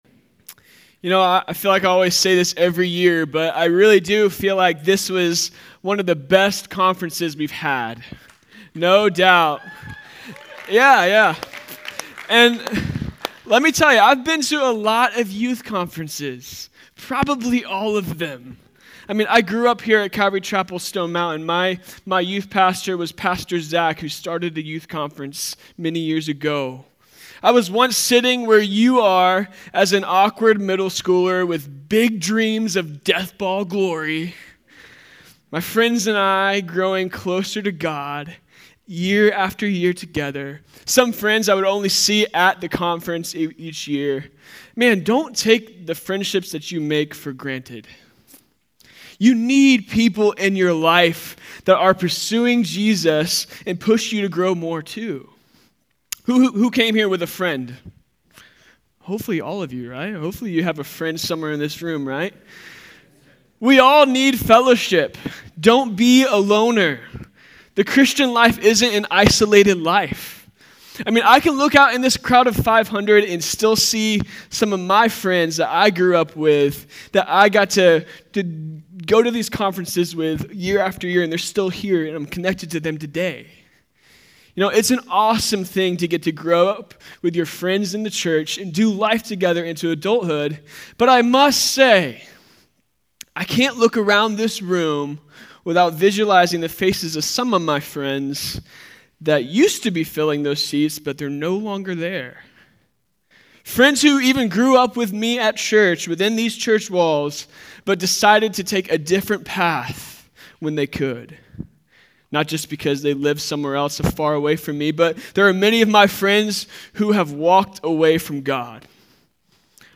Home » Sermons » Lost.
2024-DSYC Conference: Youth Conference Date